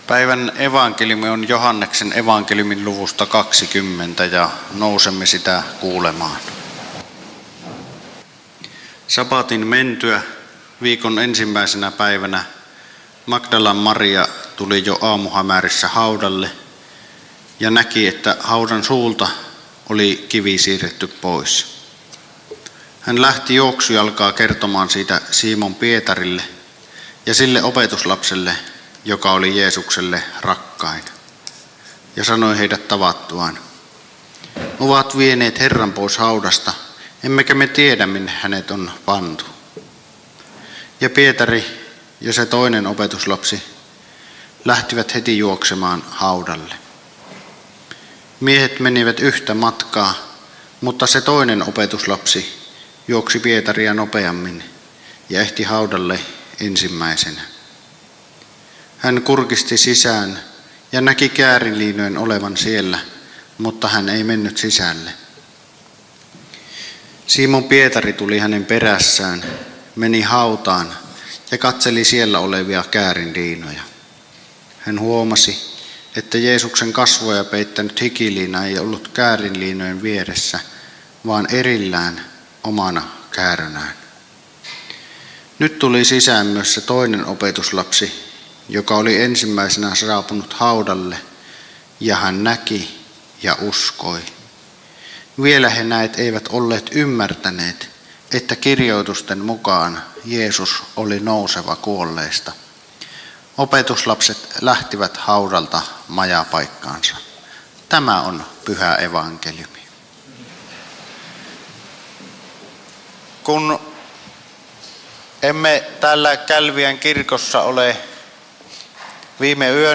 Kälviä